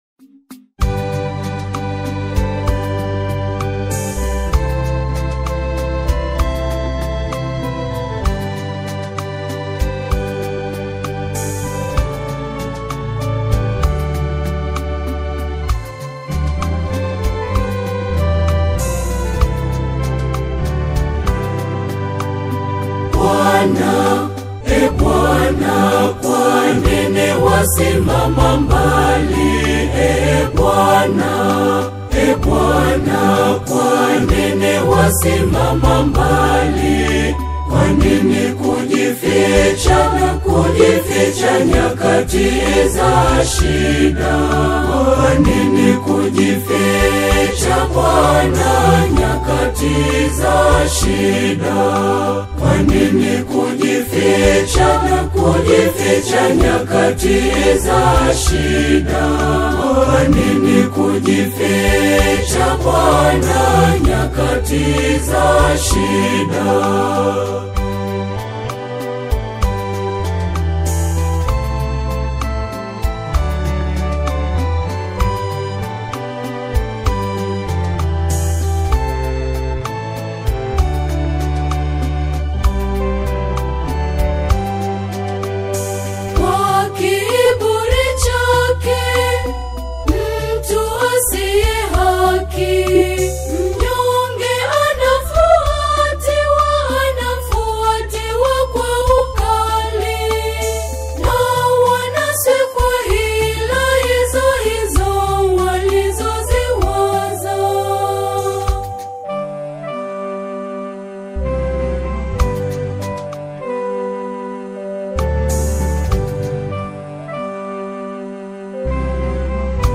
vocal ensemble
exceptional vocal blending
Tanzanian choral music